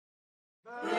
Free SFX sound effect: Gunshot Pistol.
Gunshot Pistol
546_gunshot_pistol.mp3